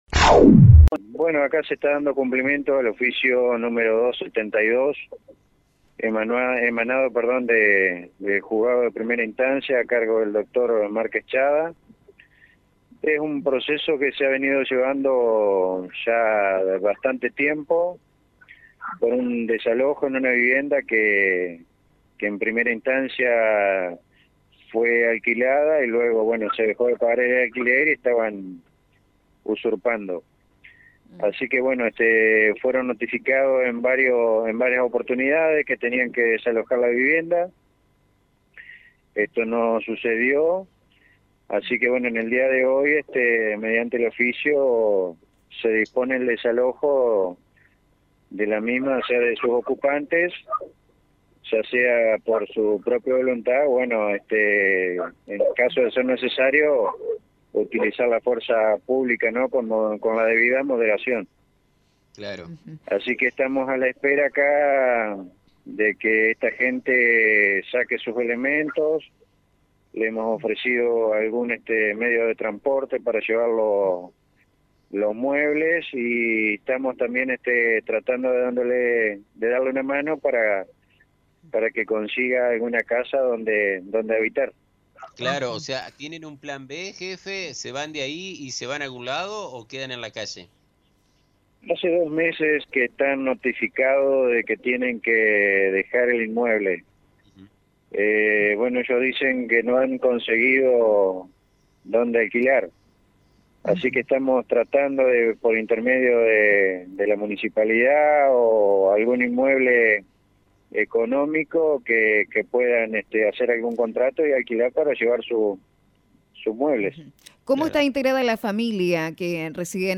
Jefe de Policía
Desalojo-Jefe-de-Policia-WEB.mp3